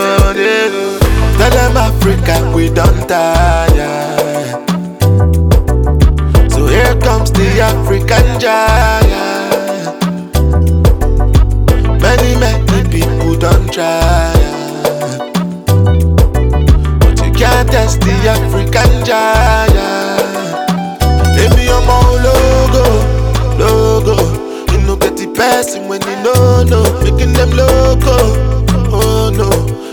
• World